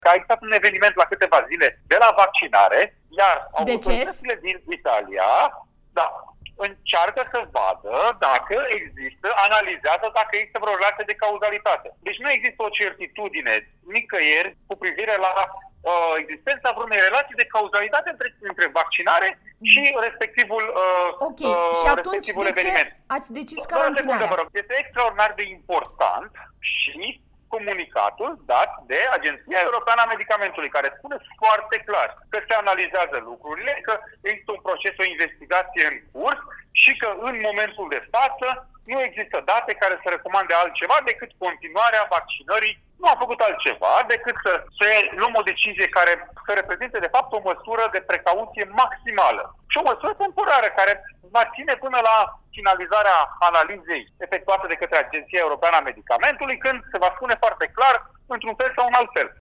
12mar-13-Baciu-INTERVIU-explica-decizie-carantinare-doze-Astra-Zeneca.mp3